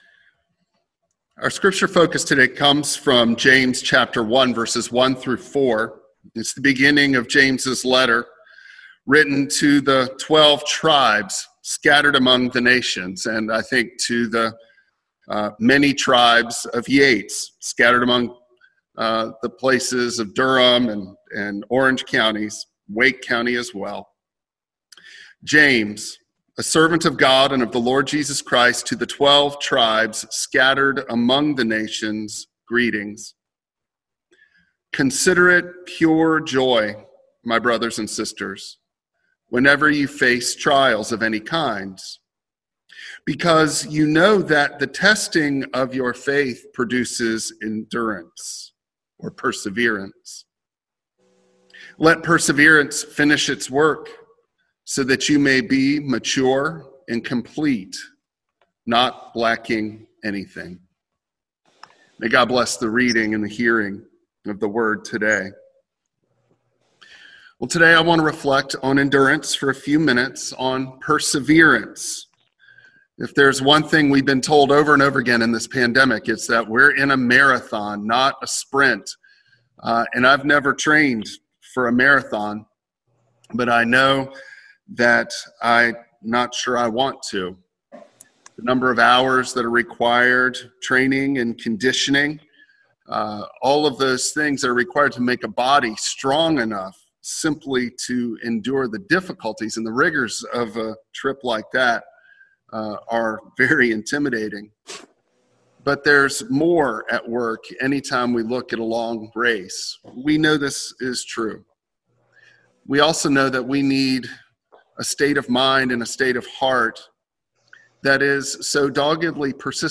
Passage: James 1:1-4 Service Type: Traditional Service Bible Text